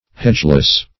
Hedgeless \Hedge"less\, a.